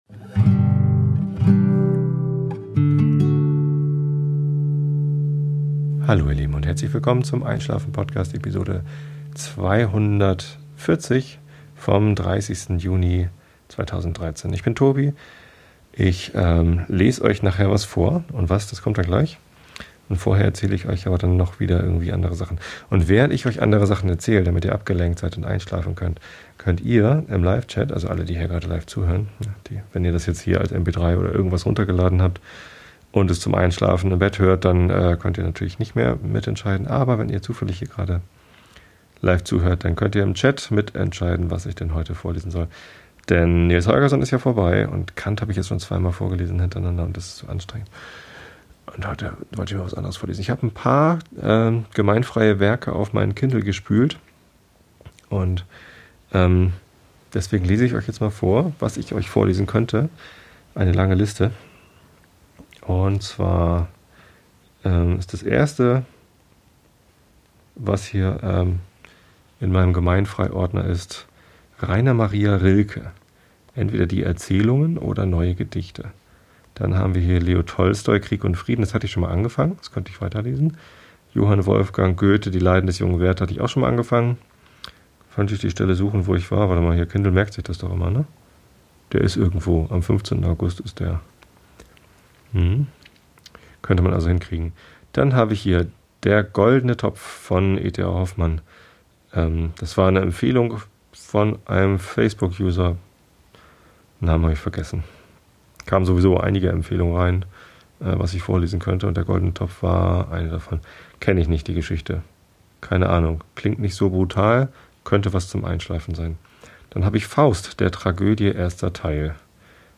Einigen konnte sich der Chat nicht, aber ich hab dann einfach ein Rilke Gedicht (Abisag), ein bisschen Kafka (Der Process) und dann Göthe (Wahlverwandschaften) vorgelesen. Vorher ging es ein bisschen um Urlaub und Bitcoin.